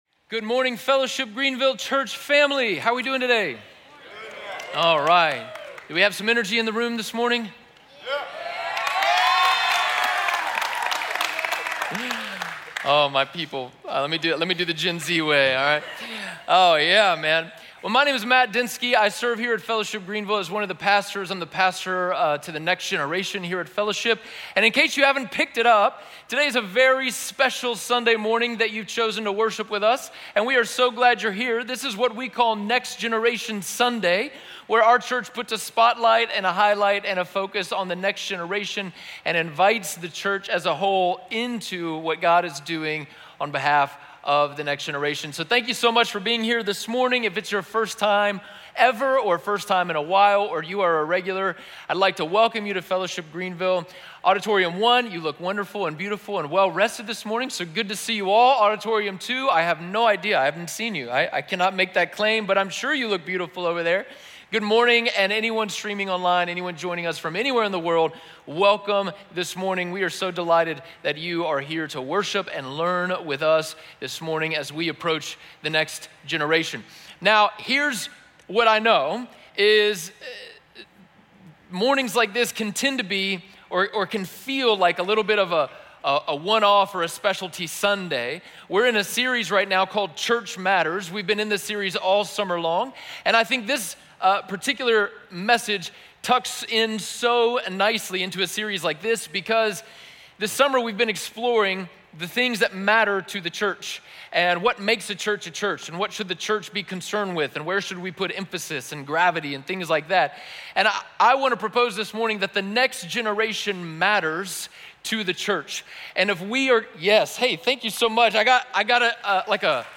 SERMON SCREENSHOTS & KEY POINTS